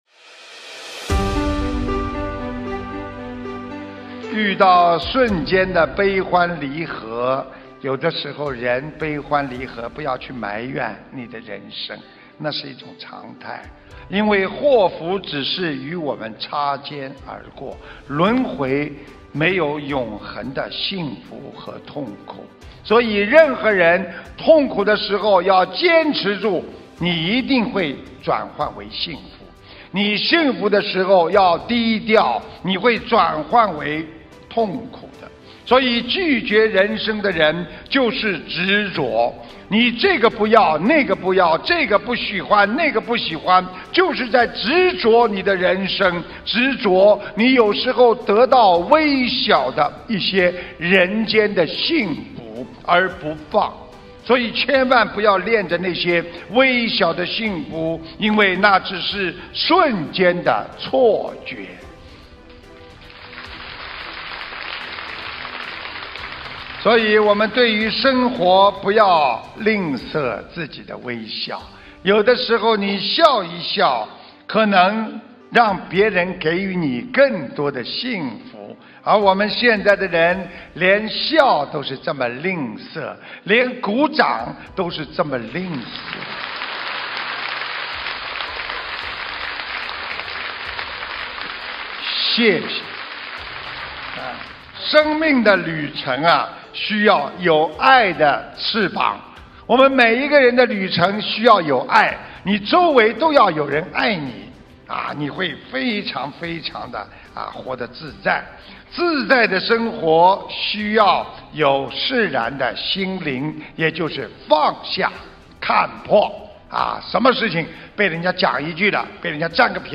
心灵净土»心灵净土 弘法视频 法会节选 视频：133.用慈爱和智慧来引领人生！
音频：用慈爱和智慧来引领人生！2019年9月11日荷兰阿姆斯特丹世界佛友见面会